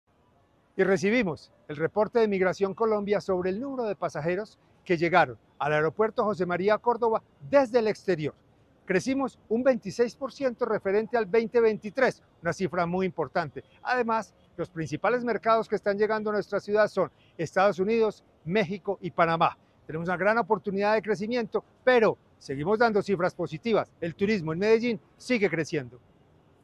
Palabras de José Alejandro González, secretario de Turismo y Entretenimiento